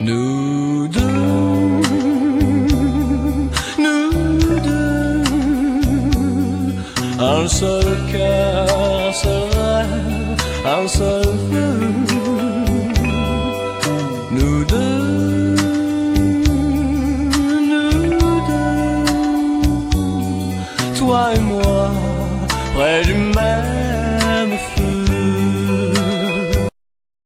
Ce que je préfère, c'est le premier accord de guitare...